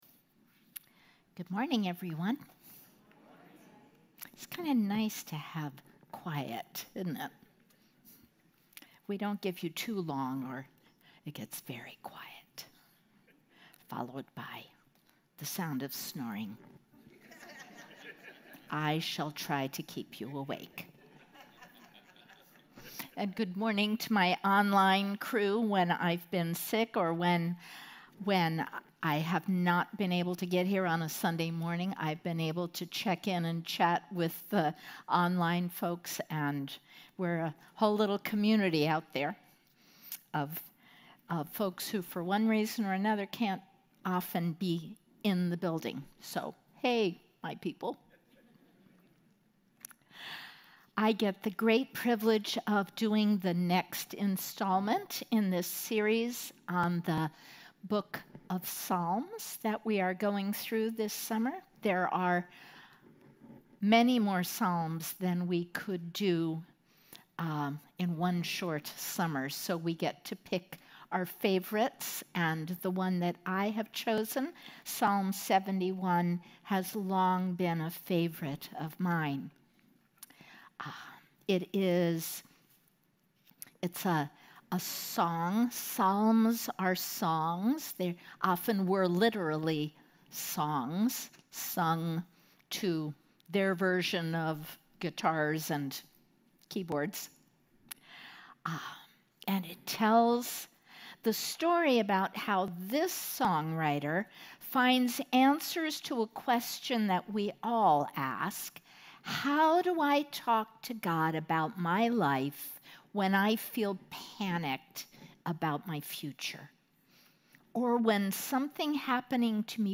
brings this morning's message.